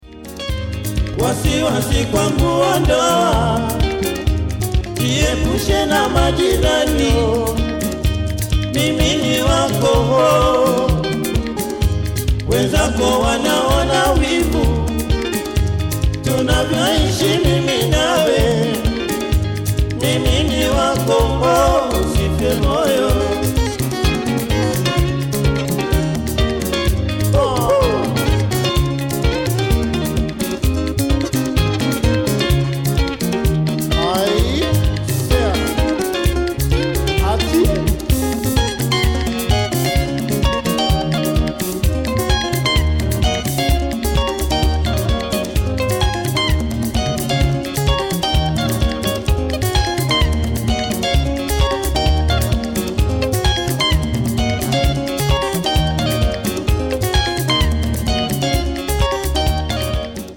recorded this album in Holland in 1991
extra guitar and percussion being added later